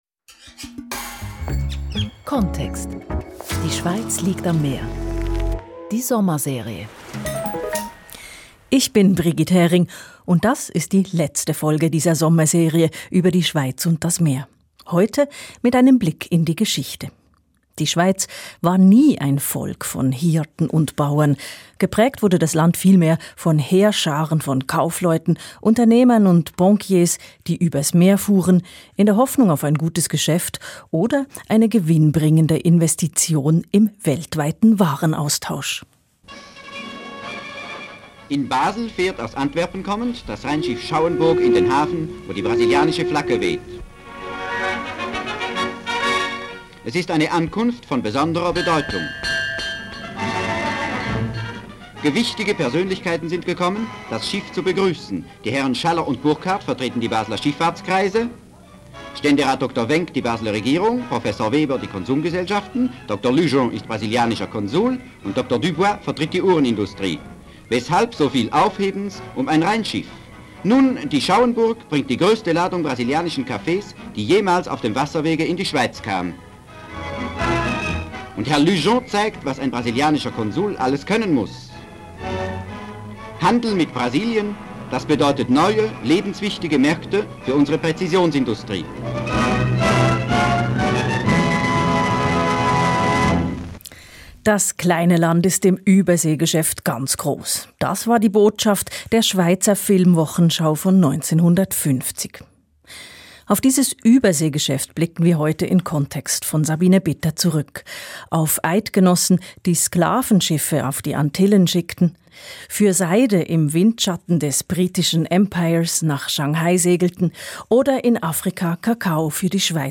Link srf: Schweiz als Handelsmacht mit einem Gespräch und einem Audiobeitrag (Kontext) zu diesem Thema